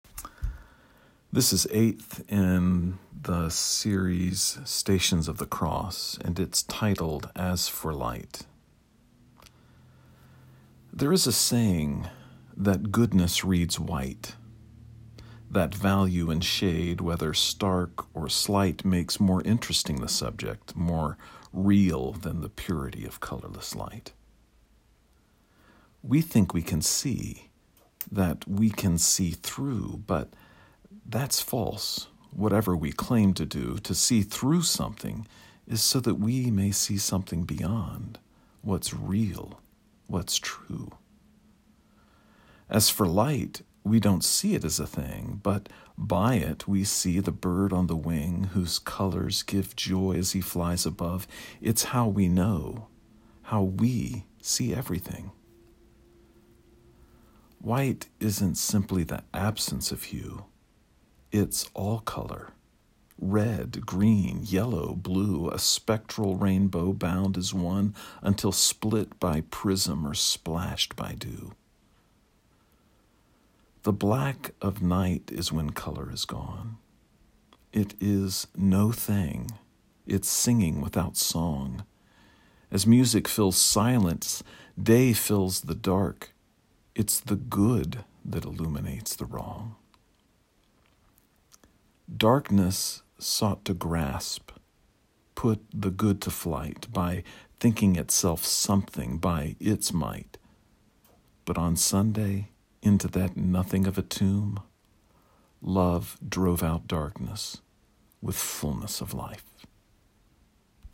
You may listen to me read the poem via the player below.